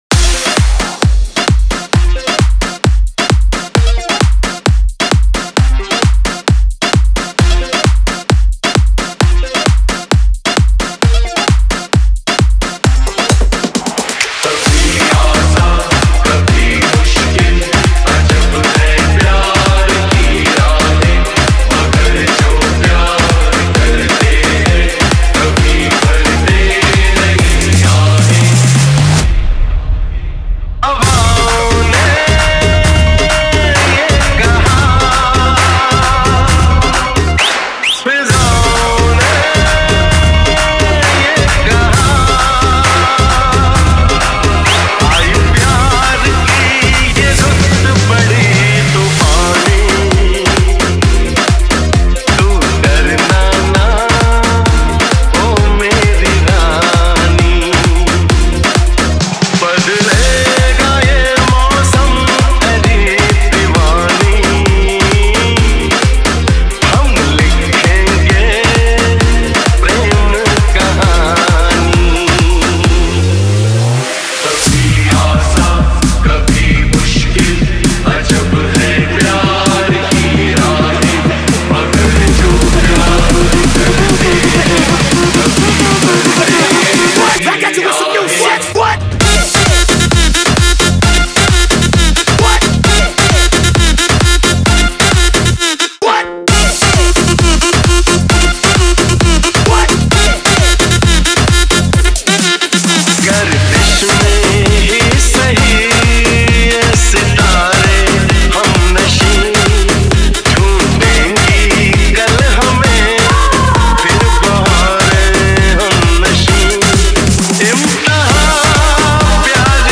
Bollywood Single Remixes